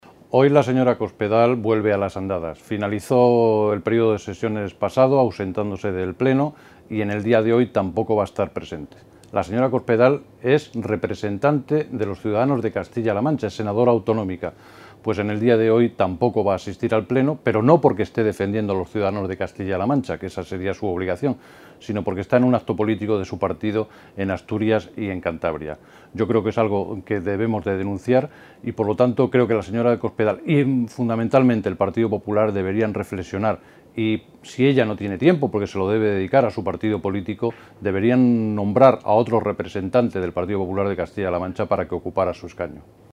El senador socialista José Miguel Camacho ha criticado hoy que la dirigente del PP y senadora en representación de las Cortes de C-LM, María Dolores de Cospedal, haya empezado el año en la Cámara Alta “como lo terminó y como viene siendo habitual en ella: haciendo novillos”.
Cortes de audio de la rueda de prensa